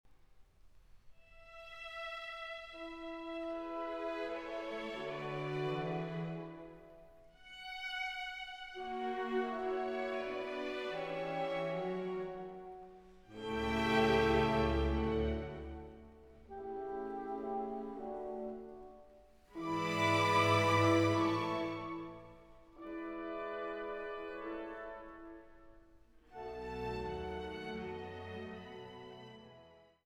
Oboe
Trompete